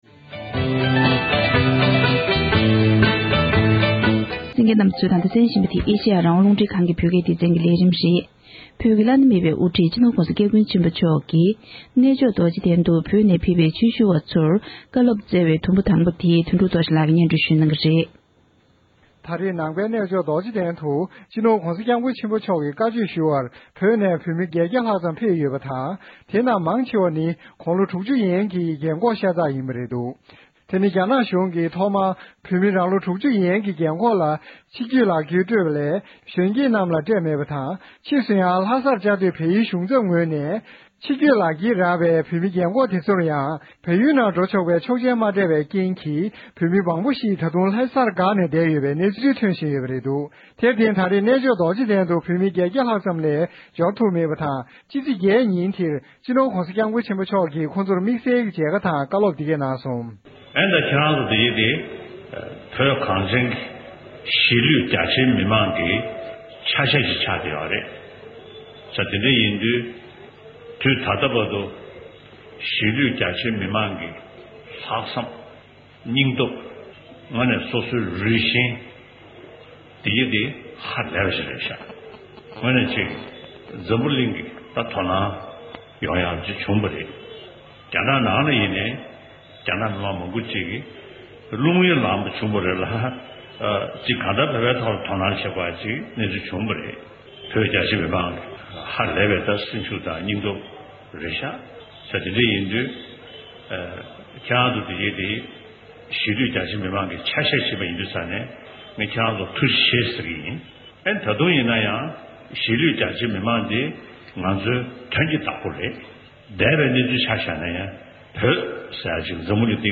༸གོང་ས་མཆོག་གིས་རྡོ་རྗེ་གདན་དུ་བོད་ནས་ཕེབས་པའི་དད་ལྡན་ཆོས་ཞུ་བར་བཀའ་སློབ་བསྩལ་བའི་དུམ་བུ་དང་པོ།
སྒྲ་ལྡན་གསར་འགྱུར།